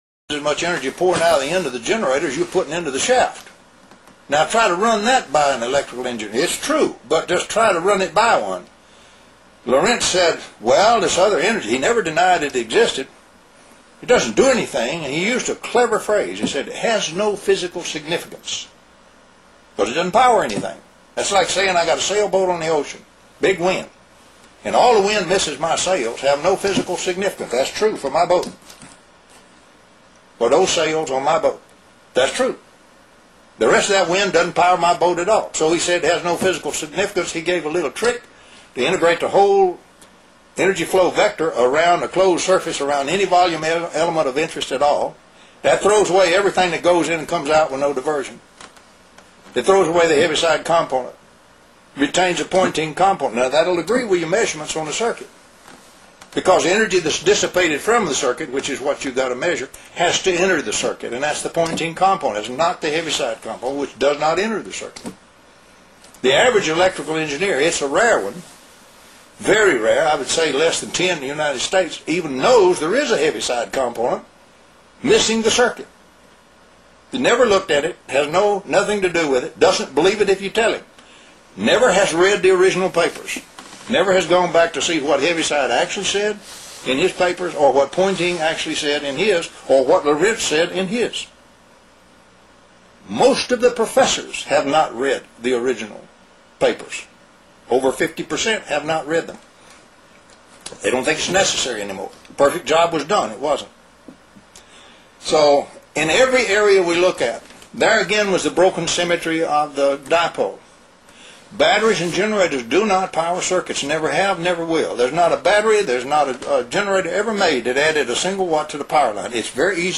Interview on H3O